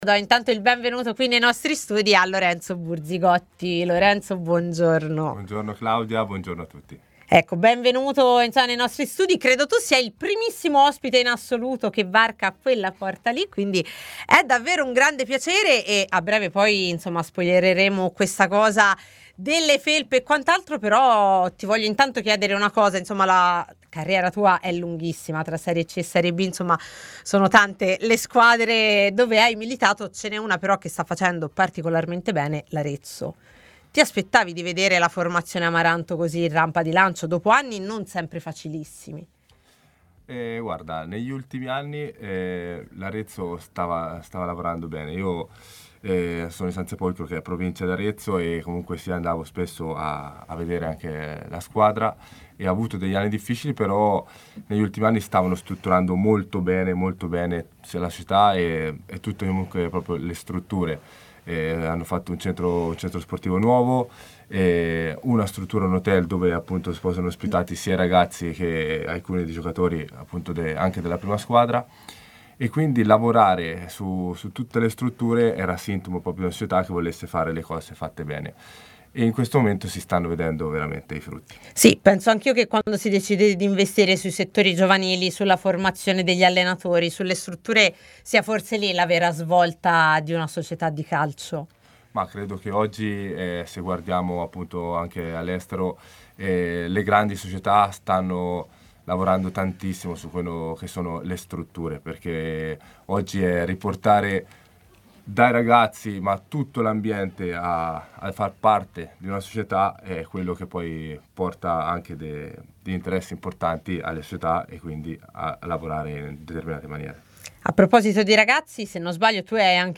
trasmissione in onda su TMW Radio e iL 61 .